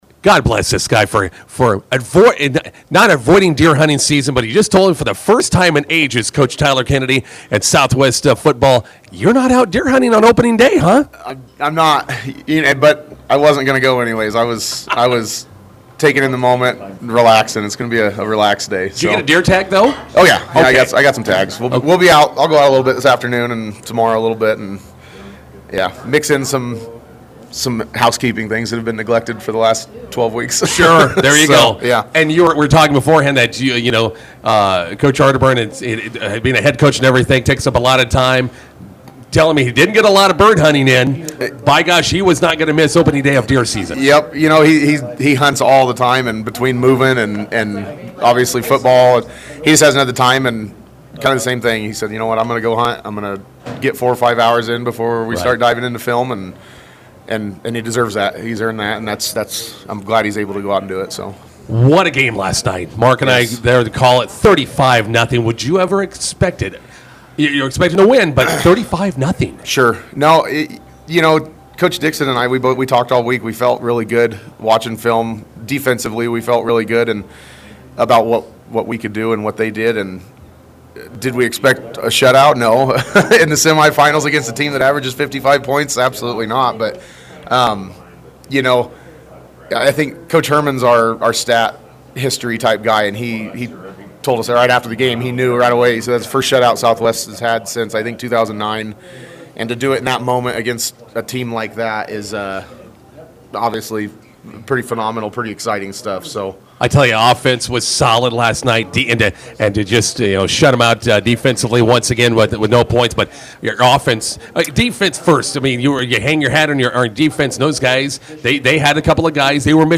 INTERVIEW: Southwest football punches state football championship game ticket with 35-0 win at Red Cloud.